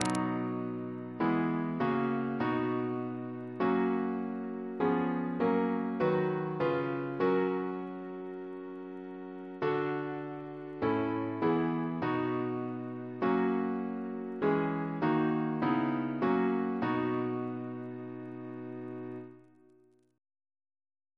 Double chant in B Composer: Charles Fisk (1925-1983) Reference psalters: H1982: S259